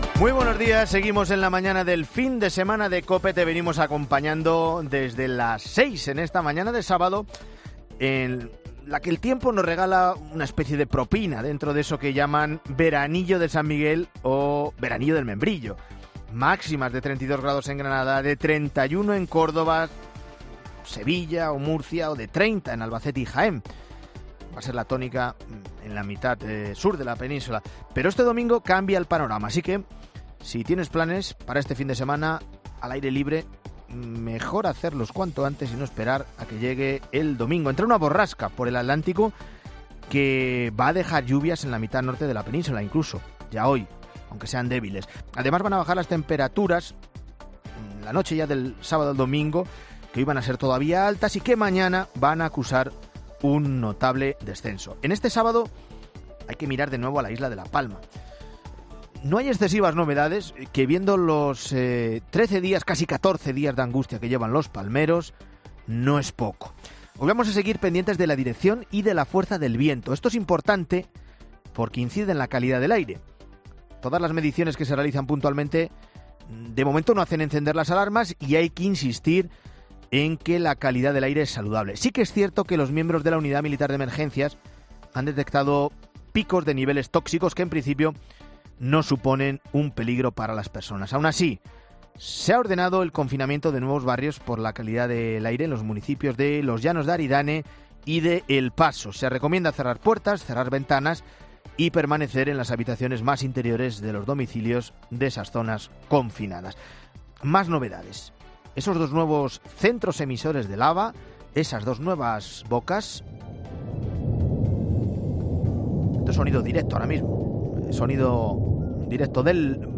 Las "algaradas mustias" en la celebración del aniversario del 1-0 o el precio de la luz, también entre los temas del monólogo de Cristina López...